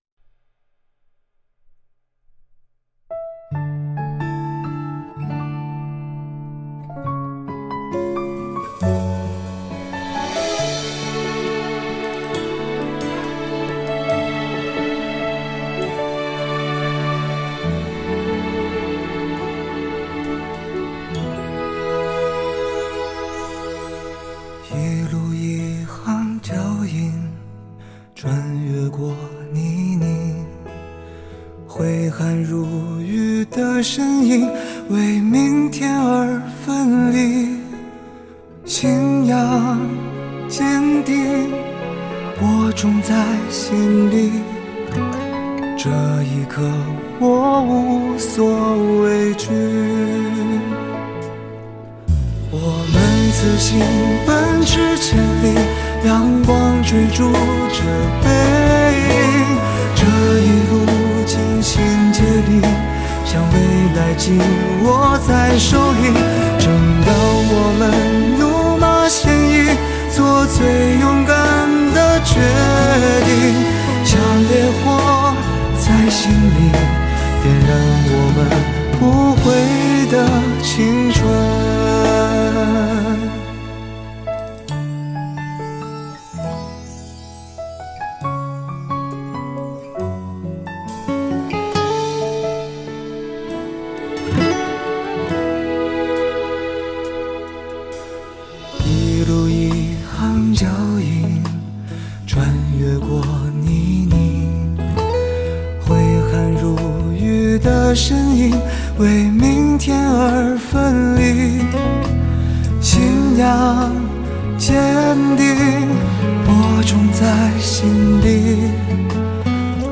经典的情歌，优美的旋律，非常感谢楼主分享！